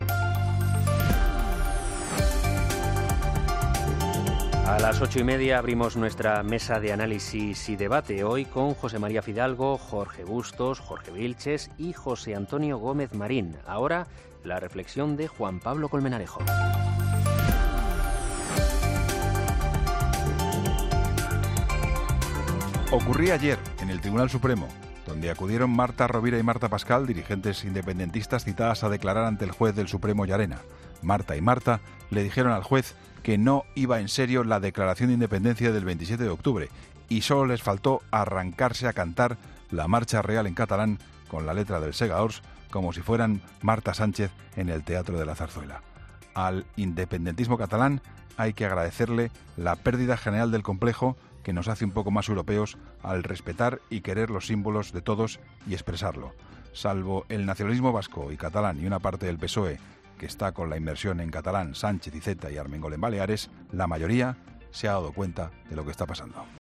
AUDIO: Escucha el análisis del director de 'La Linterna', Juan Pablo Colmenarejo, en 'Herrera en COPE'